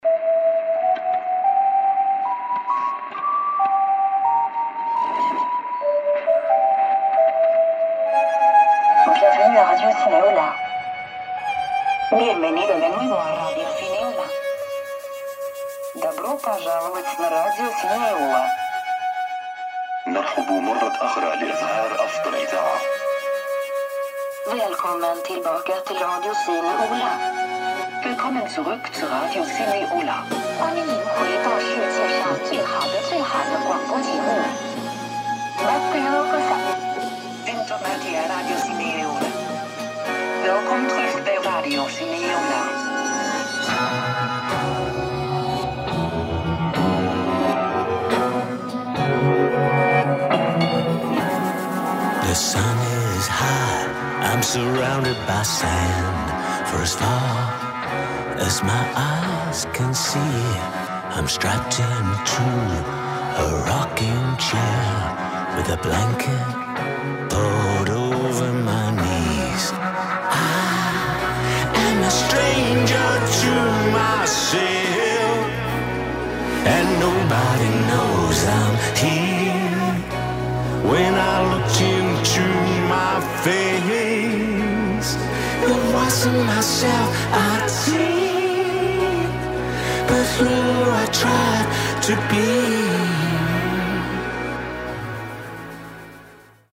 Space interview